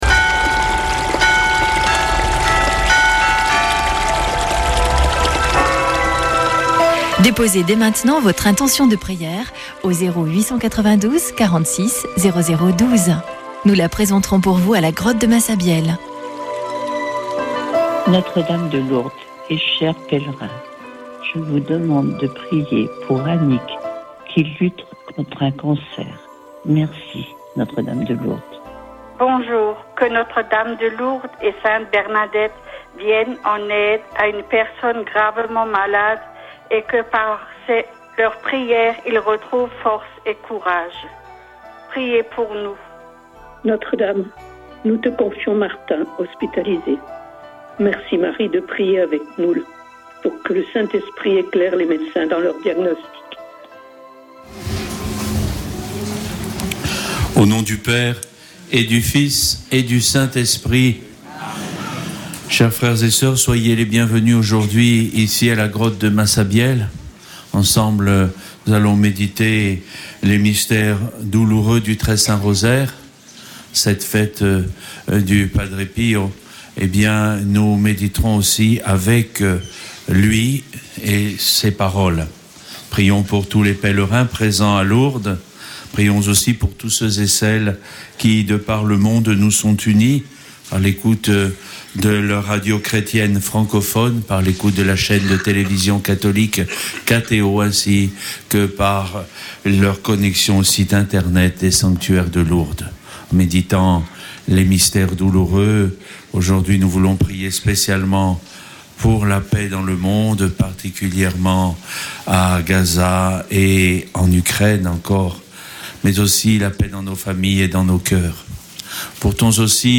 Chapelet de Lourdes du 23 sept.
Une émission présentée par Chapelains de Lourdes